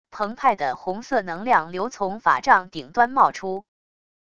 澎湃的红色能量流从法杖顶端冒出wav音频